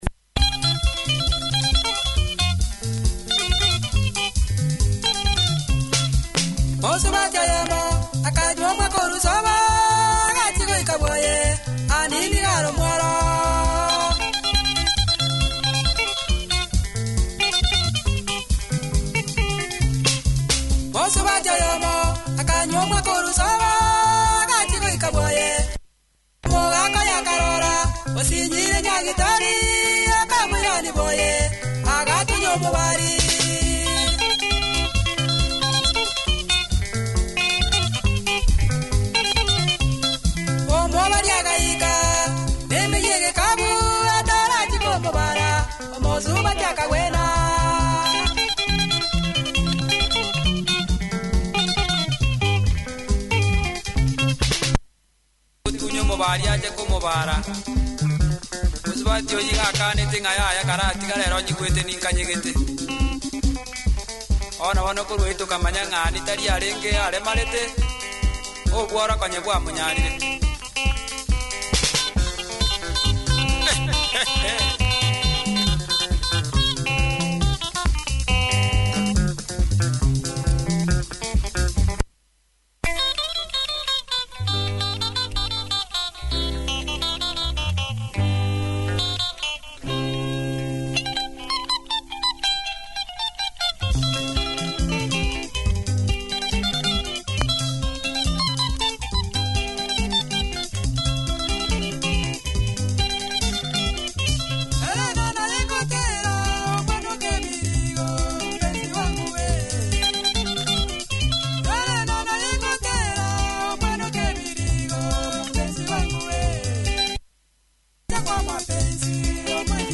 Tight Kisii Benga, punchy production good tempo, loud too.